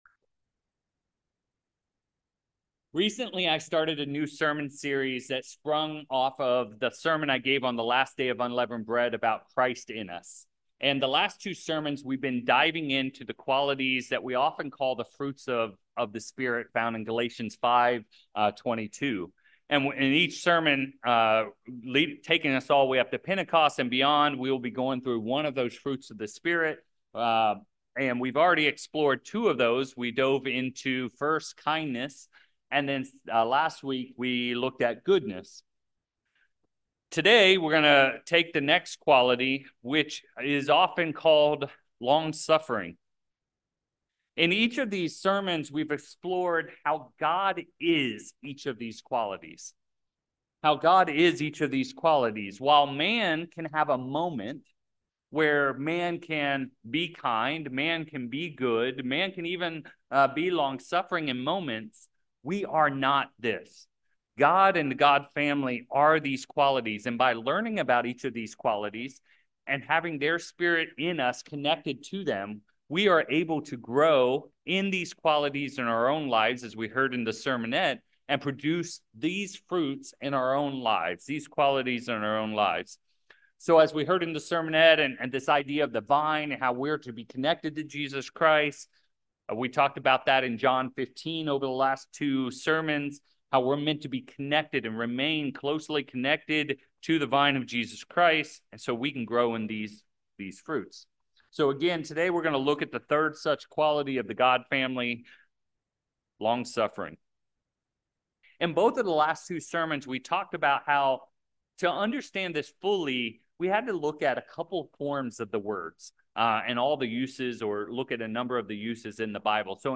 6/1/24 In this third sermon of this series on nine qualities of the God Family, we explore the misunderstood fruit of Long-Suffering. We explore the clear meaning of the two root words as we come to the Biblical useage and understanding of this word. In this sermon, we'll explore 6 Biblical themes tied to this fruit/quality of God.